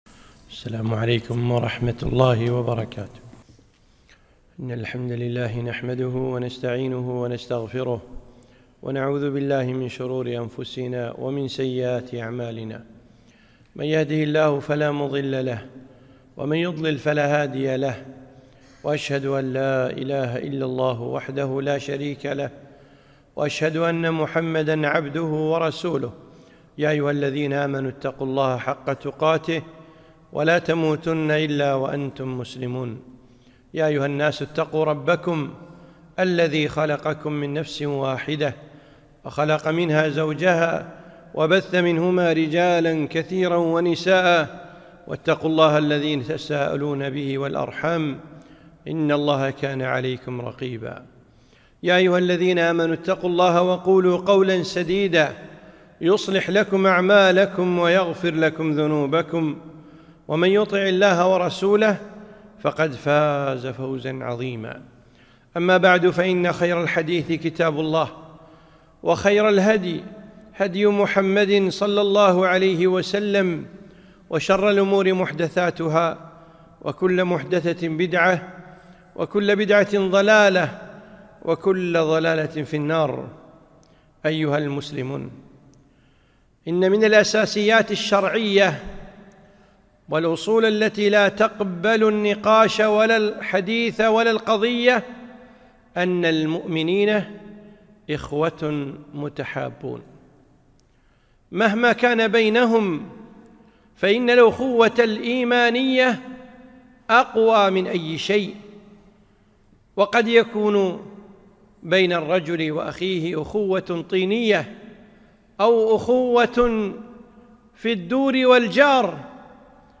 خطبة - الجسد الواحد